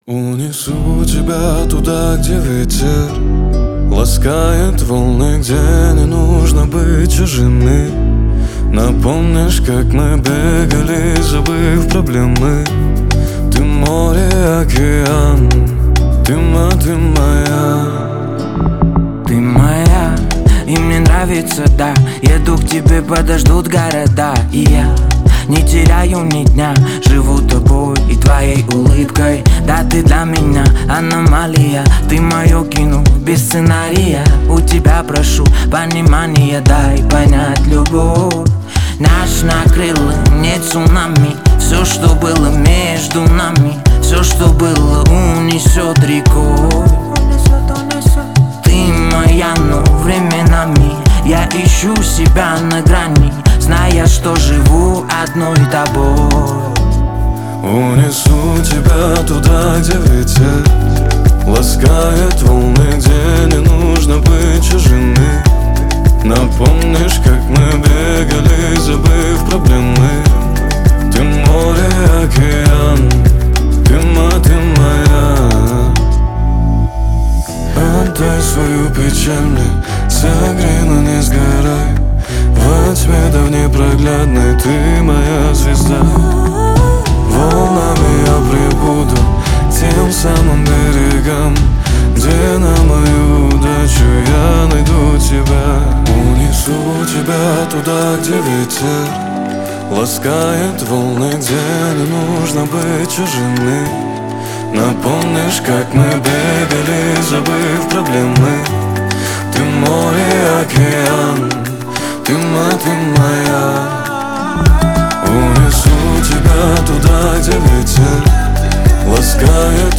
Категория: Хип-Хоп